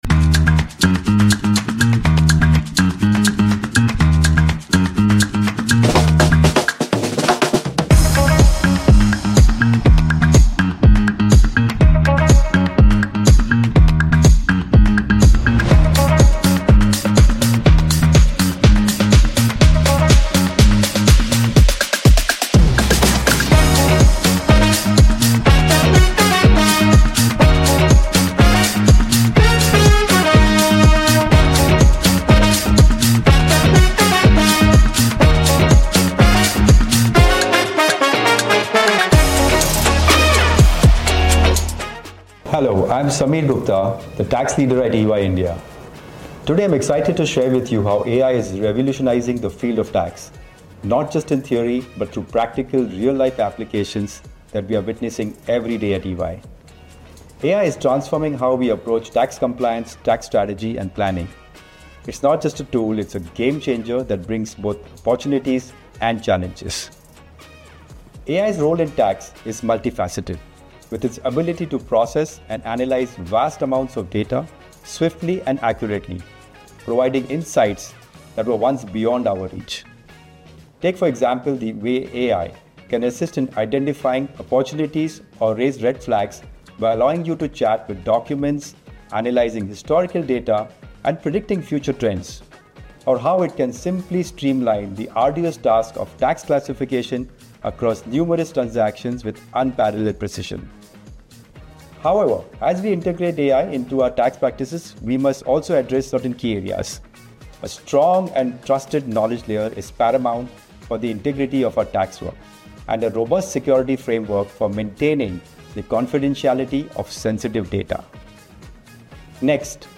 The EY India Insights podcast features conversations with experts on a wide range of topical subjects like ESG, technology, and economic and policy changes that are shaping the thought process of the C-Suite, and are likely to be the cornerstone of a transformative business.